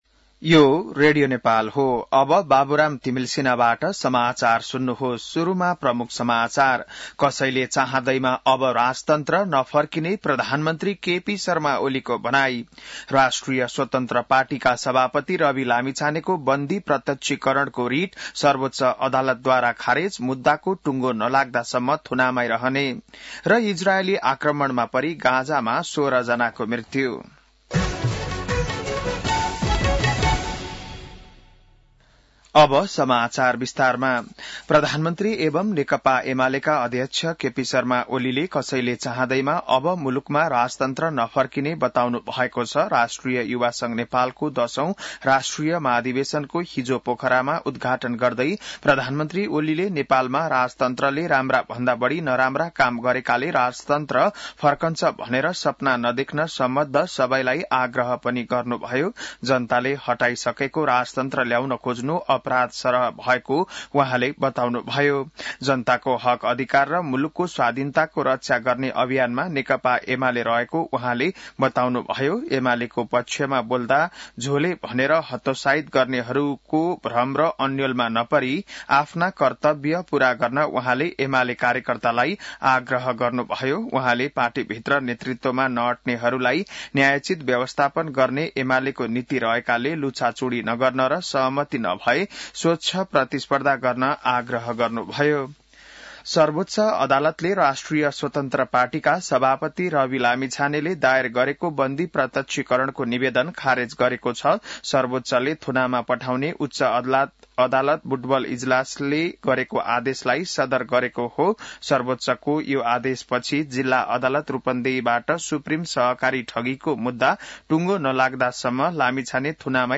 An online outlet of Nepal's national radio broadcaster
बिहान ९ बजेको नेपाली समाचार : १० जेठ , २०८२